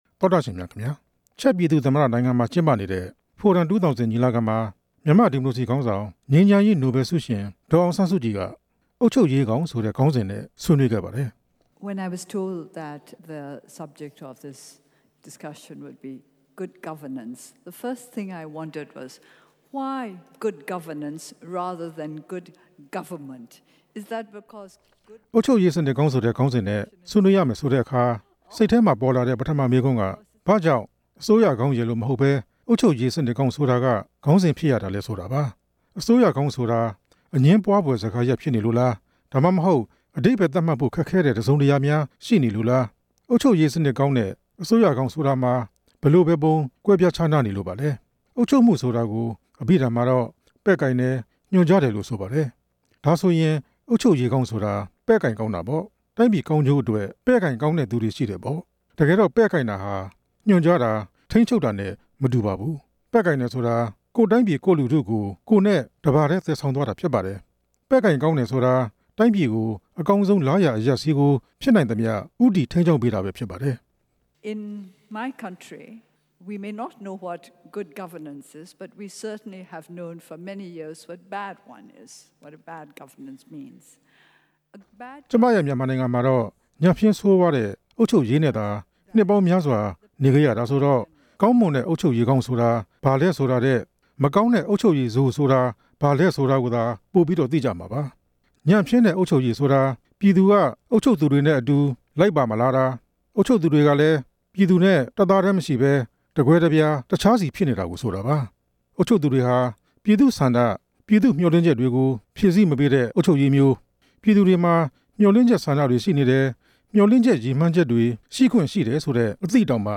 အမျိုးသားဒီမိုကရေစီအဖွဲ့ချုပ် ဥက္ကဌ ပြည်သူ့လွှတ်တော် ကိုယ်စားလှယ် ငြိမ်းချမ်းရေးနိုဘယ်ဆုရှင် ဒေါ်အောင် ဆန်း စုကြည်ဟာ ချက်သမ္မတနိုင်ငံ ပရပ်မြို့မှာ ကျင်းပနေတဲ့  Forum 2000 ညီလာခံ ဒုတိယမြောက်နေ့မှာ “The Road to Good Governance”  အုပ်ချုပ်ရေးစနစ်ကောင်းတစ်ရပ်ဆီ သွားရာလမ်းဆိုတဲ့ ခေါင်းစဉ်နဲ့ ဆွေးနွေးခဲ့ပါတယ်။